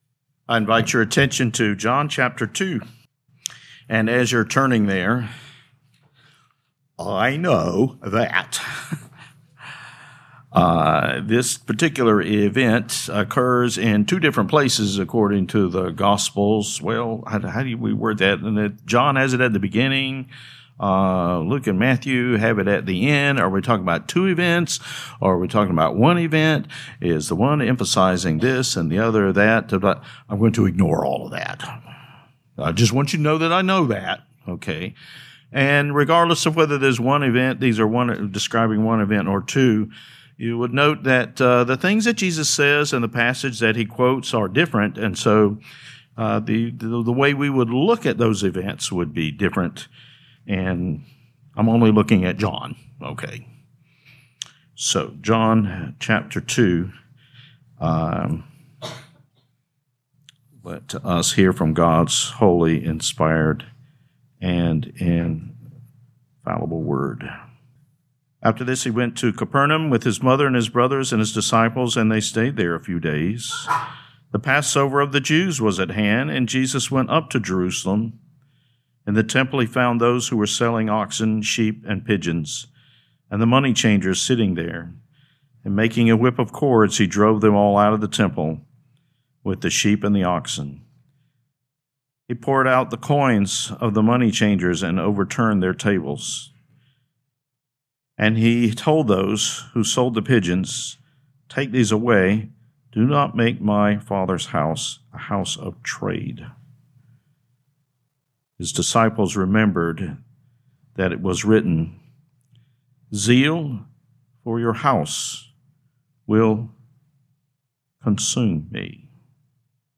2026 John Evening Service Download